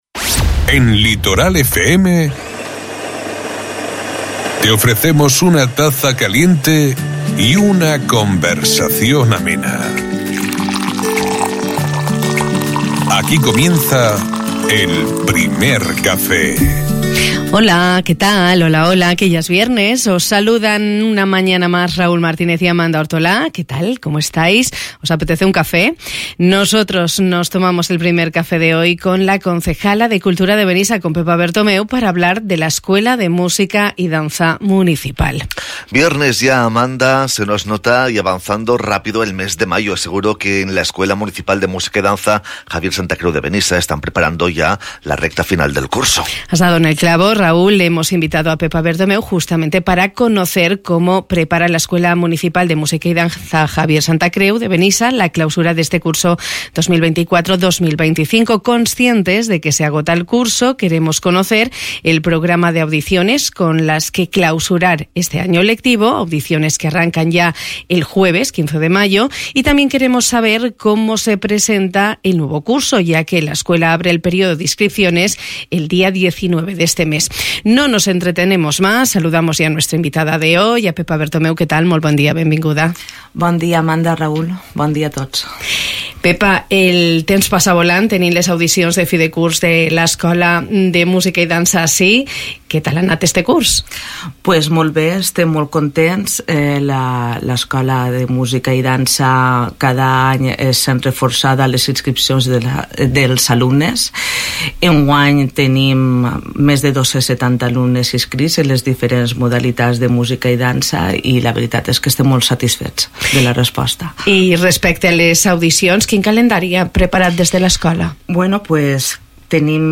Esta mañana hemos compartido el Primer Café de Radio Litoral con la concejala de Cultura de Benissa, Pepa Bertomeu, con la que hemos conversado sobre la Escuela de Música y Danza Municipal Javier Santacreu.